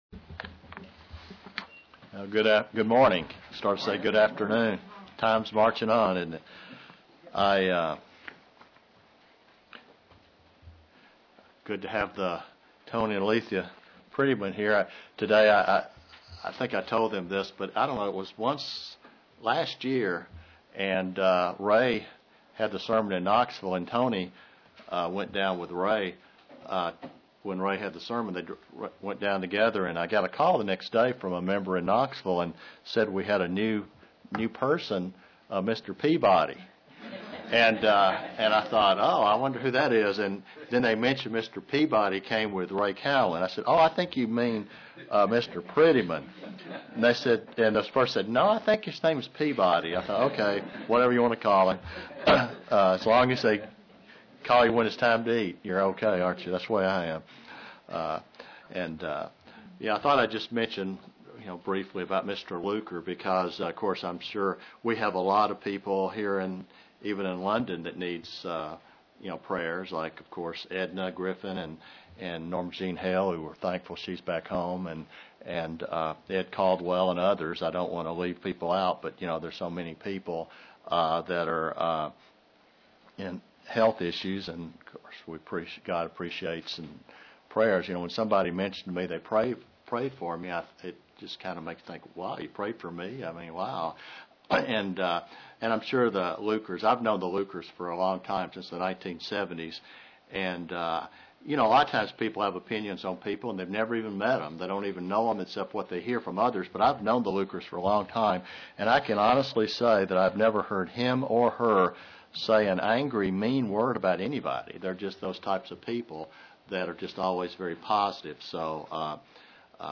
Print God expects a Christian to spend a lifetime of repenting UCG Sermon Studying the bible?